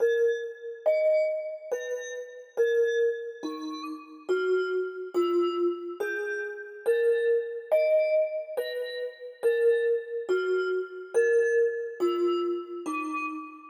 标签： 70 bpm Trap Loops Bells Loops 2.31 MB wav Key : Unknown
声道立体声